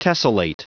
Prononciation du mot tessellate en anglais (fichier audio)
Prononciation du mot : tessellate